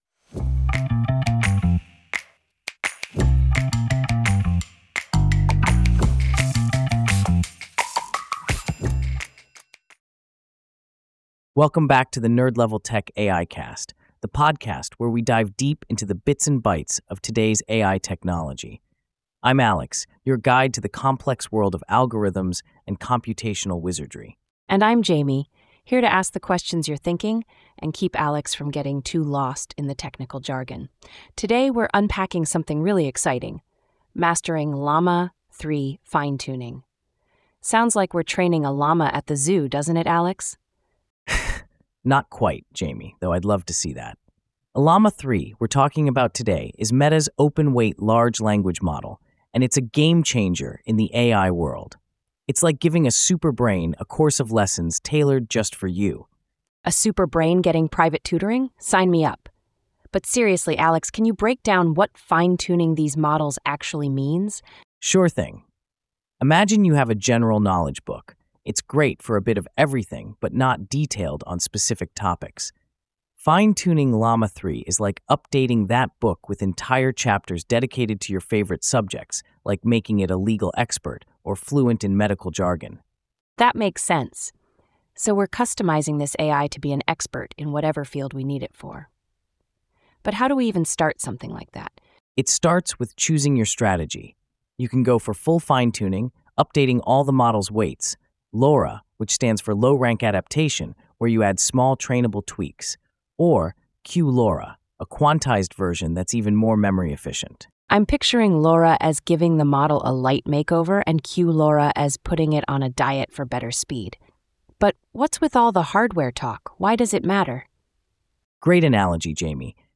ai-generated